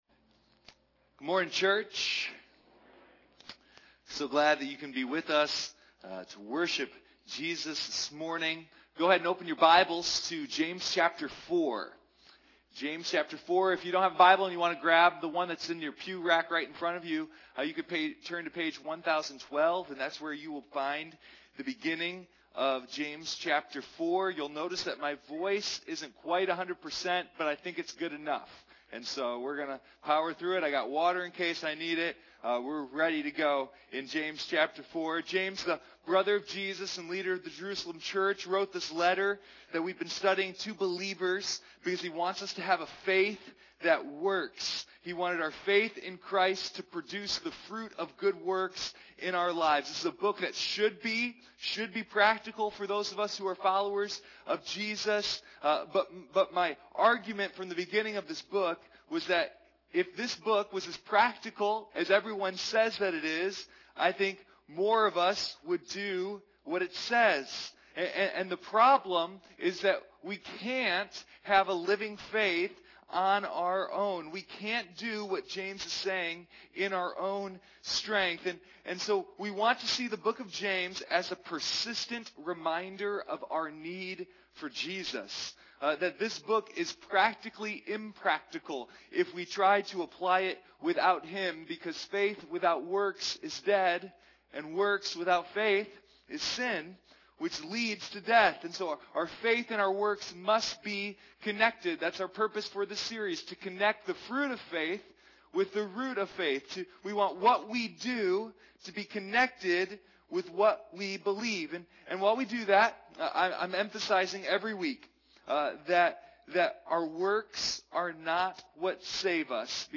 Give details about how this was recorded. Sunday Morning Living Faith: The Book of James